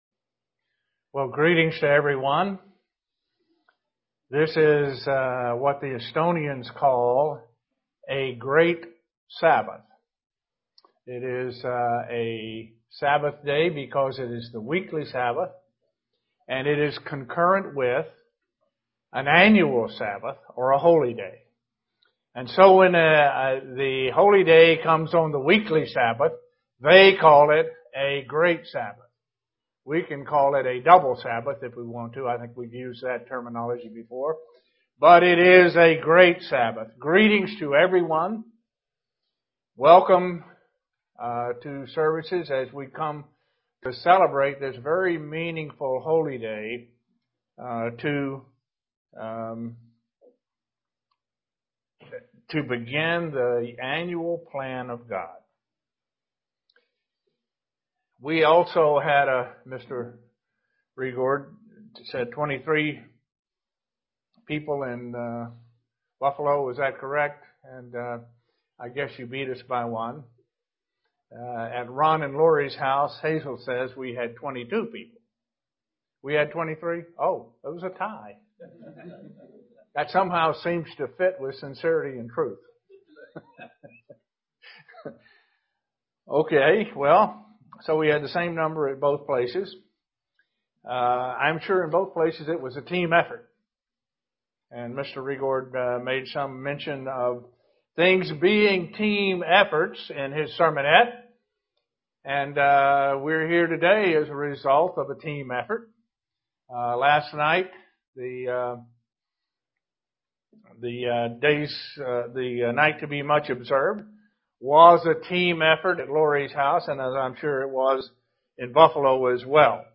Defining the meaning of Ebenezer. The stone of help is Christ. This message was given during the Days of Unleavened Bread.